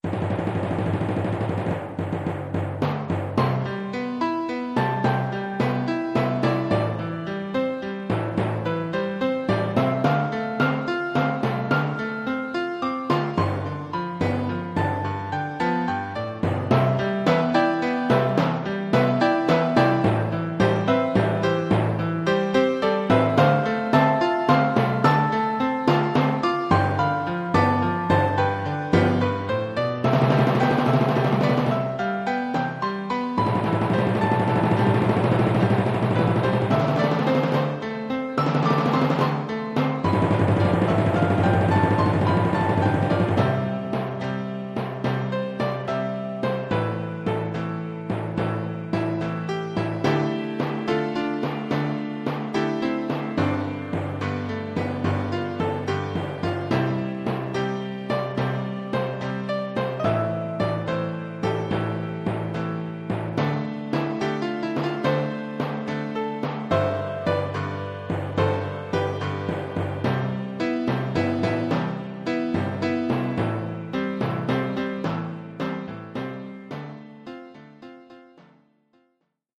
Oeuvre pour timbales et piano.